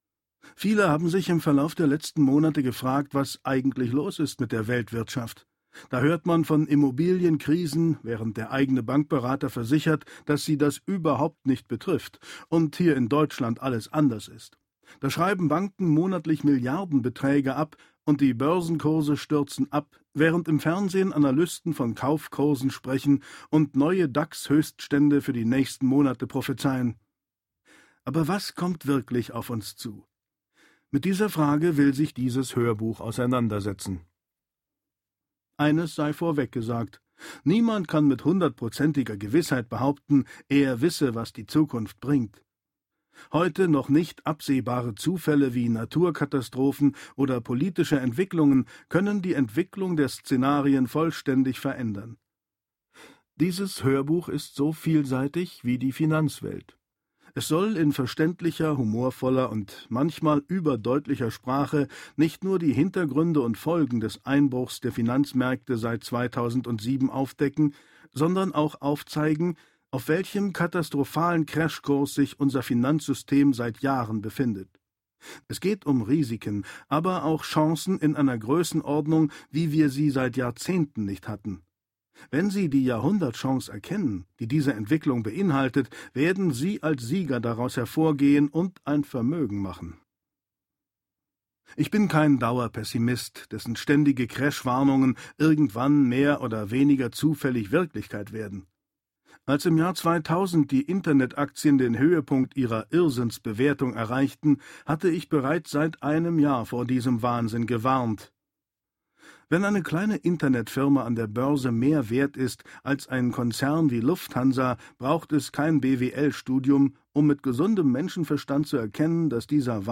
Detlef Bierstedt (Sprecher)
Reihe/Serie DAV Lesung